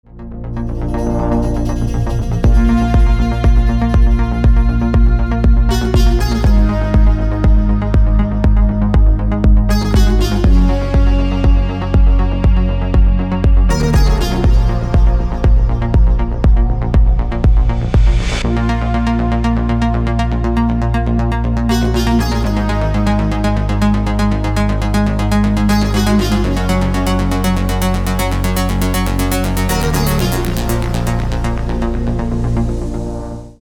• Качество: 320, Stereo
громкие
dance
Electronic
EDM
без слов
progressive house
индийские мотивы
Melodic Techno